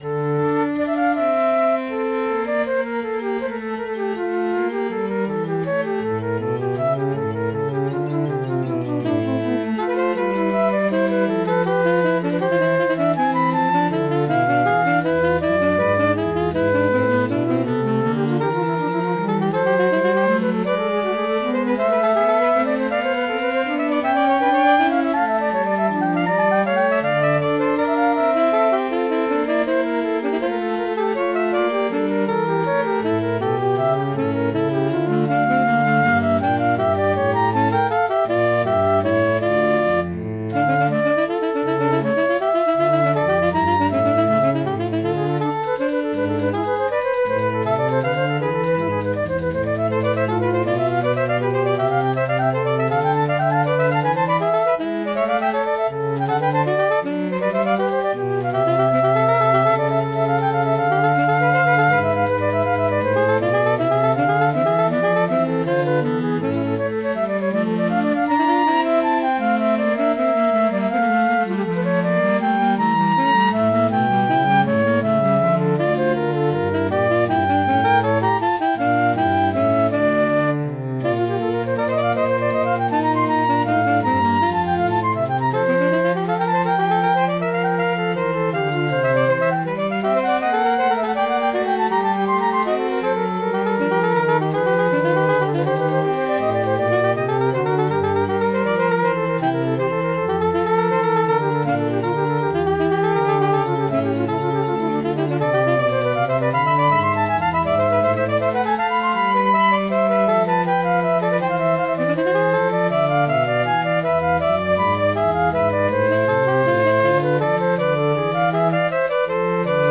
To avoid having copyright problems, this is an electronically produced version of the tune with synthesized flute, soprano sax, and ‘cello doing the voices. The bouncy triplets that put it into 9/8 make this wistful D minor piece a contemplative ride with thousands of colors and emotions.